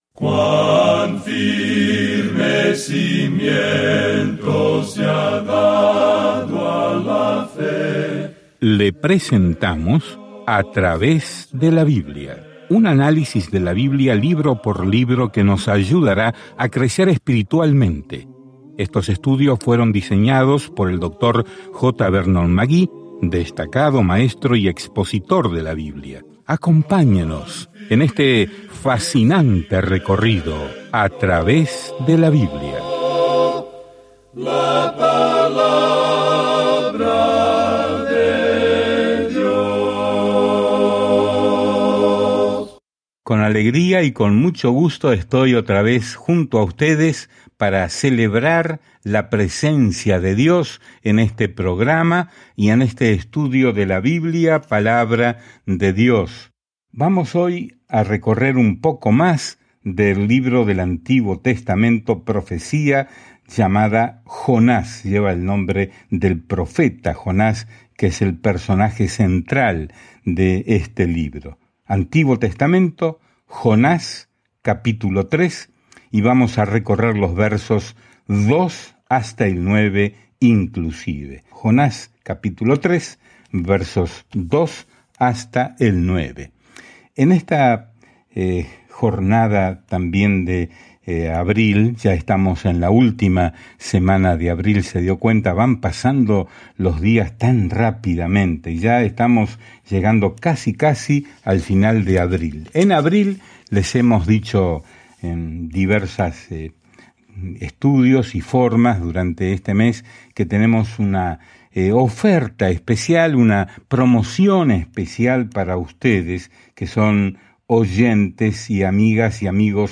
Viaje diariamente a través de Jonás mientras escucha el estudio de audio y lee versículos seleccionados de la palabra de Dios.